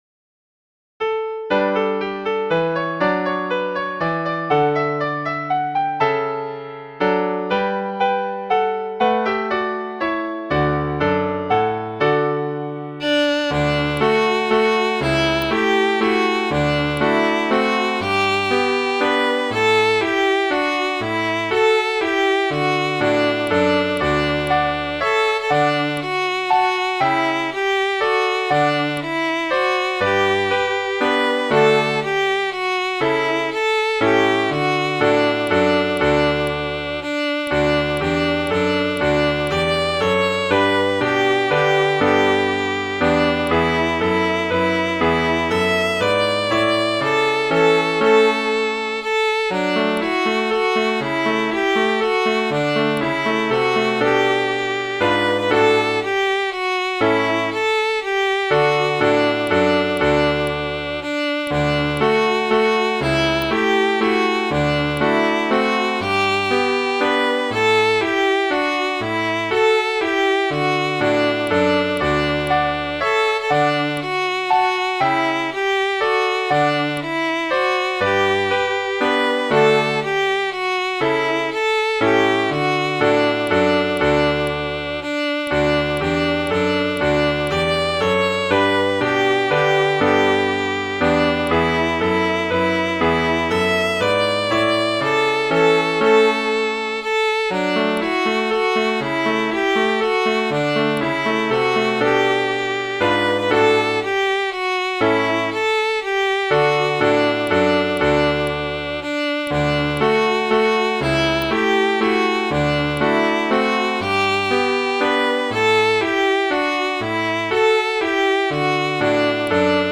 Midi File, Lyrics and Information to Derry Down Dale